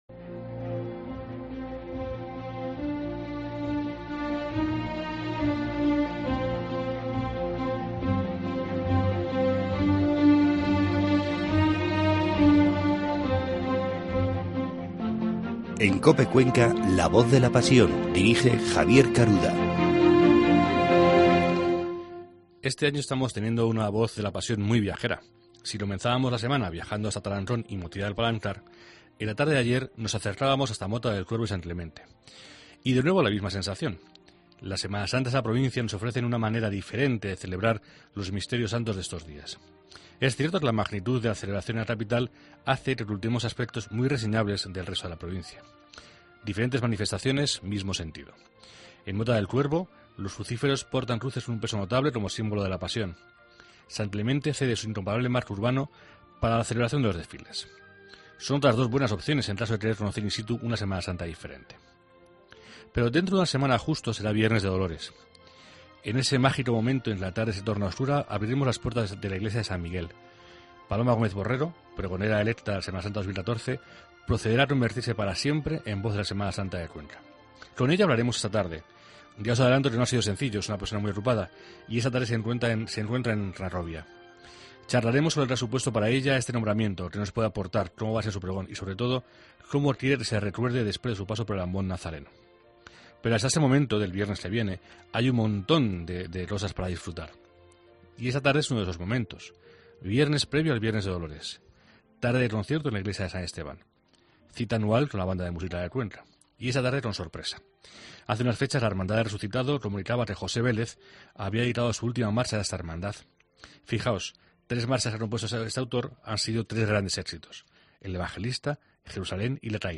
Concluiremos el programa con la audición de la grabación de esta marcha realizada la semana pasada por la Banda de Música de Cuenca.